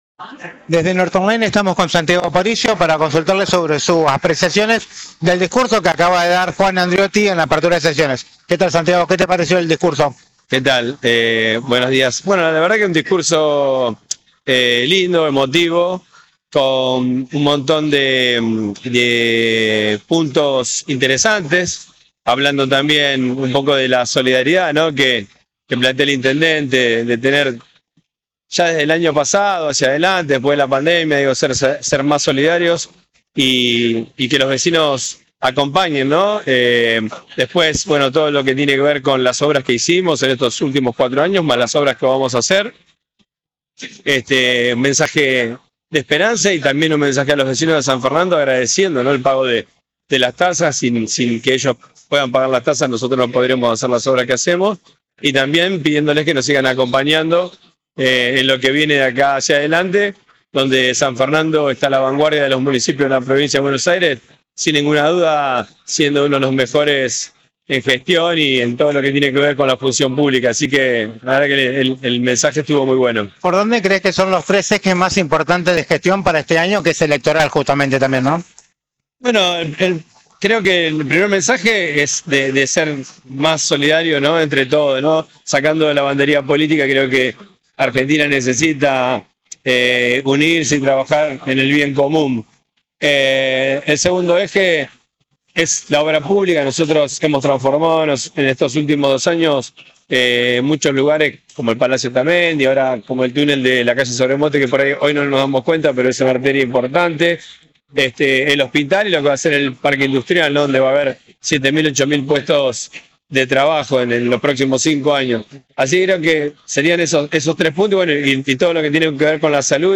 El presidente del Honorable Concejo Deliberante (HCD) del distrito conversó en exclusiva con NorteOnline acerca de la apertura de sesiones a cargo del intendente, Juan Andreotti.